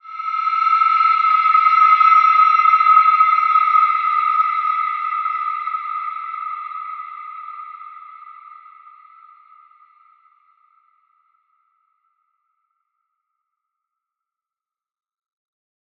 Wide-Dimension-E5-f.wav